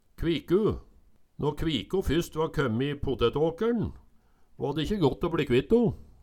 kviku - Numedalsmål (en-US)